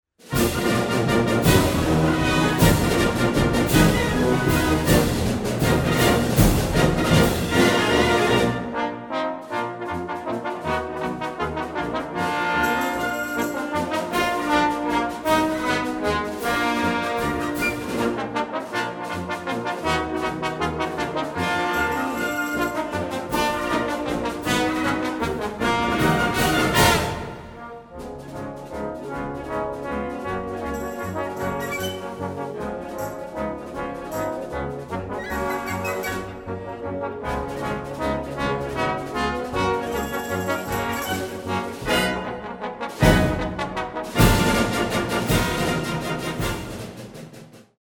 Voicing: Trombone Trio w/ Band